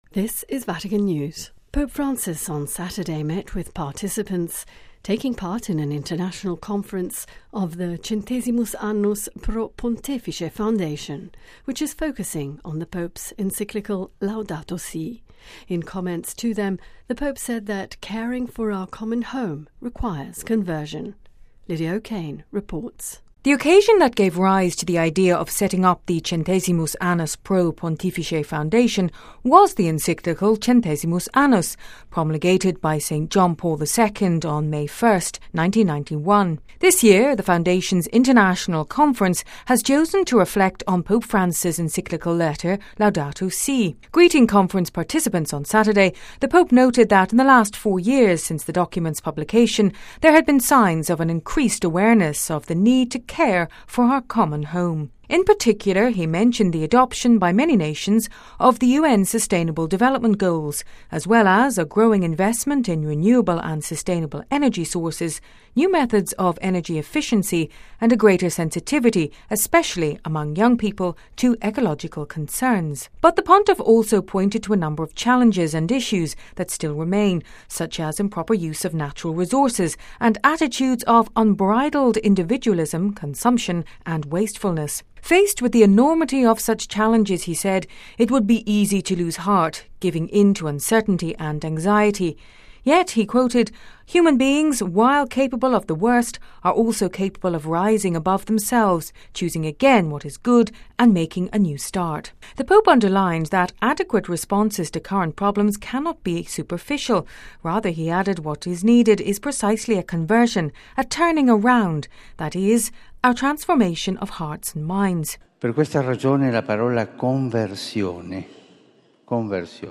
Vatican Radio service on CAPP Foundation 2019 International Convention – 8th June 2019
Vatican_Radio-Service-on-2019-International-Convention-1.mp3